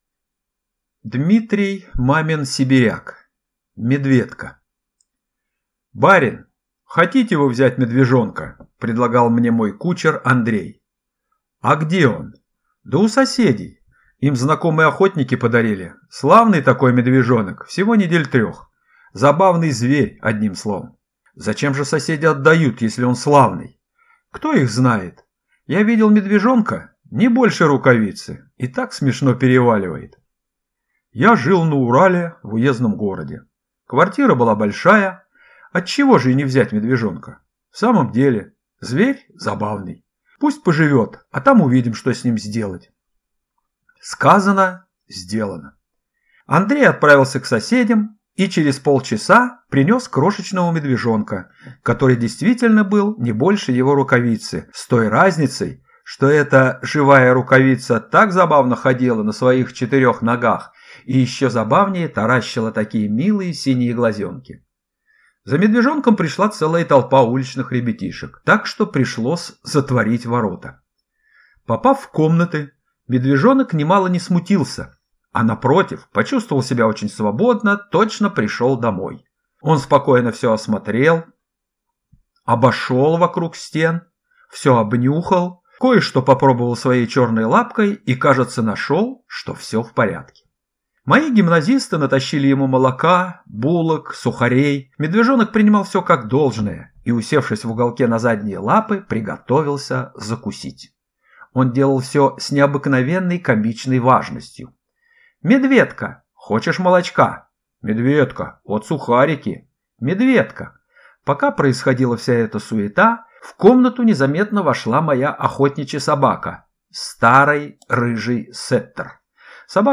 Аудиокнига Медведко | Библиотека аудиокниг
Aудиокнига Медведко